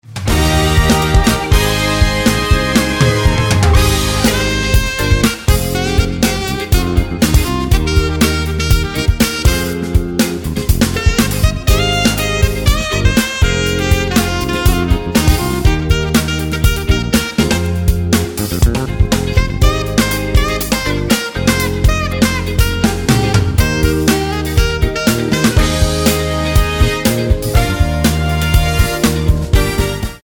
Tonart:Eb ohne Chor